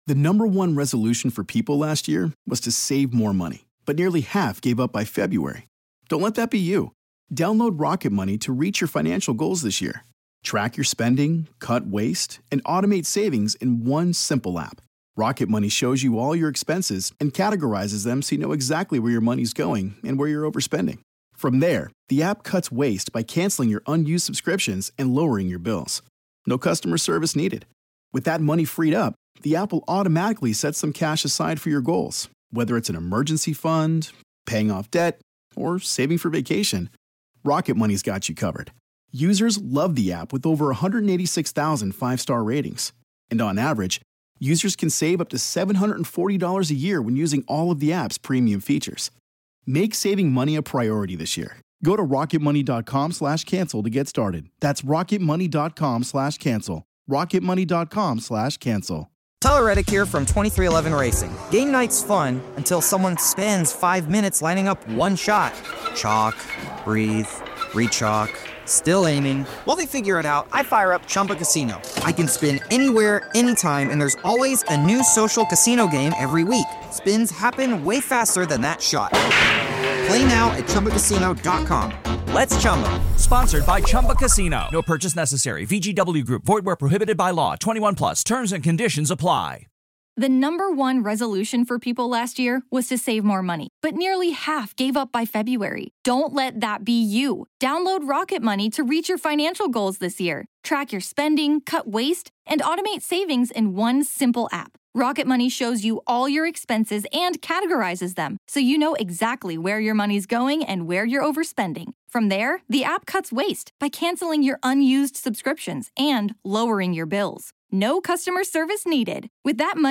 Haunting real ghost stories told by the very people who experienced these very real ghost stories.